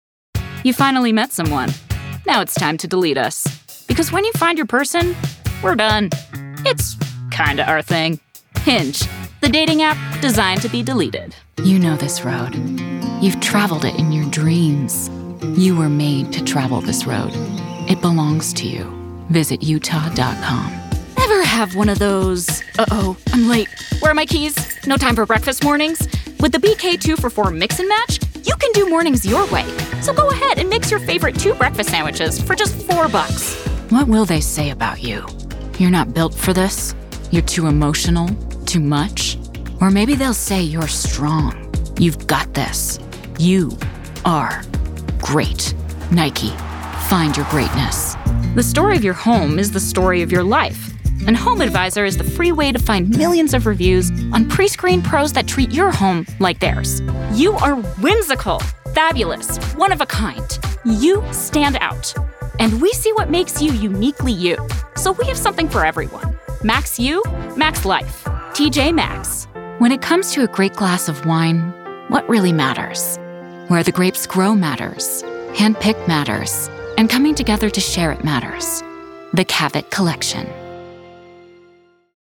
Teenager, Young Adult, Adult, Mature Adult
Has Own Studio
COMMERCIAL 💸